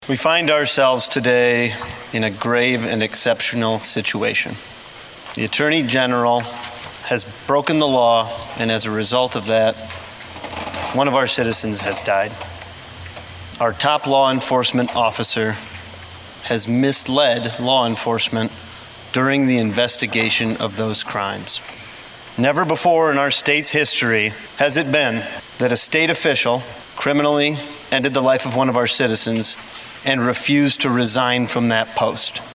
District 24 Republican Representative Will Mortenson of Pierre introduced the Articles of Impeachment during a special session of that legislative body today (April 12, 2022) in Pierre.